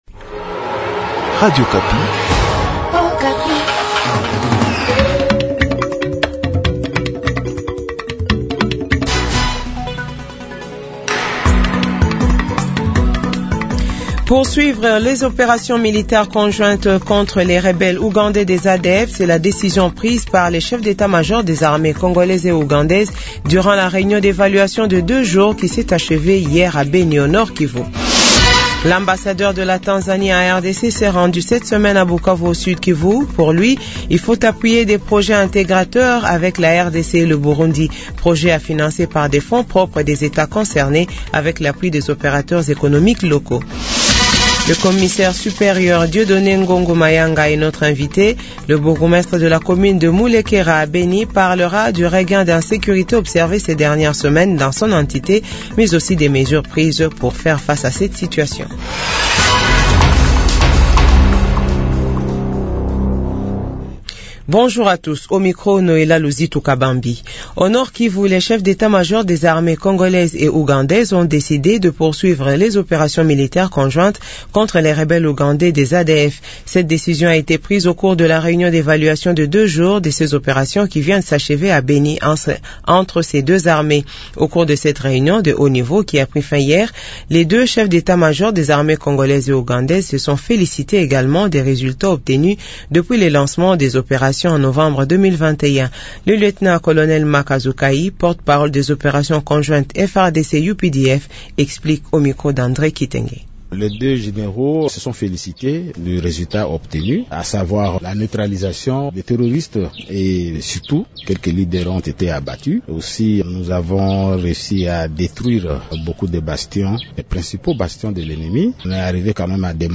Journal francais de 15h00